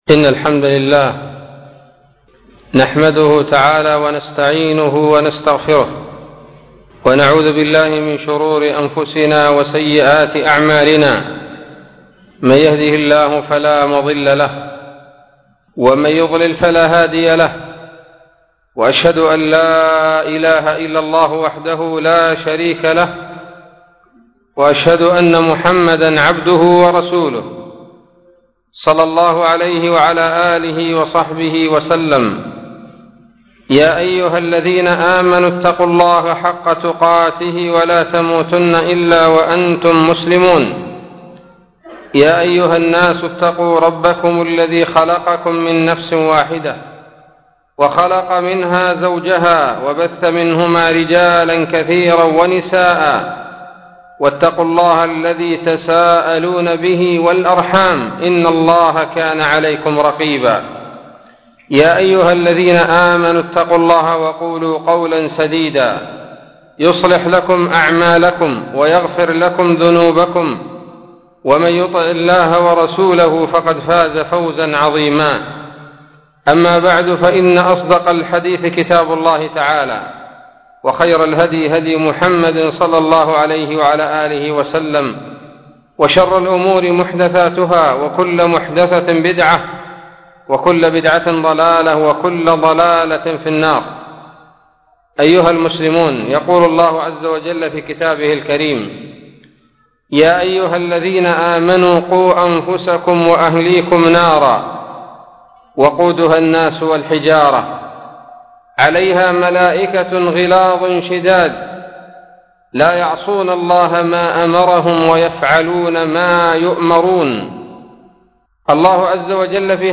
خطبة بعنوان : ((أسباب دخول النار))